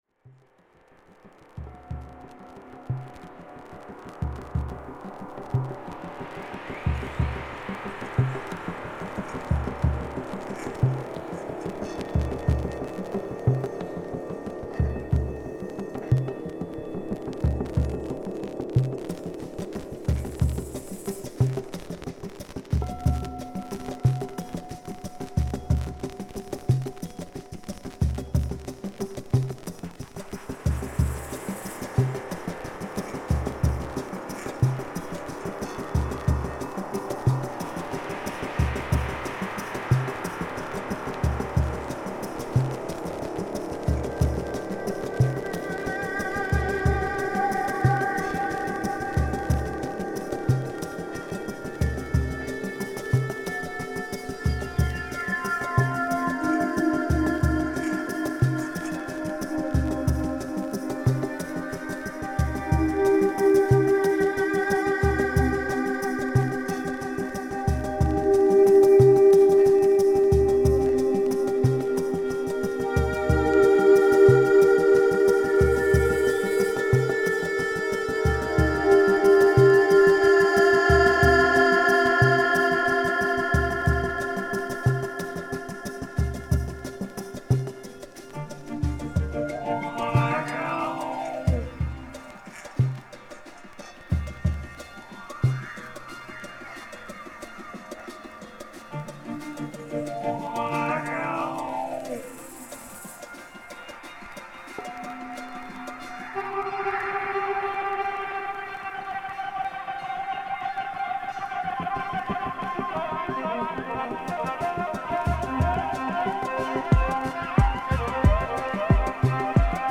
キラー・ディープ・ディスコ・ダブ収録！
＊試聴はA→B→C→Dです。
Deep House , Disco Dub , Electronic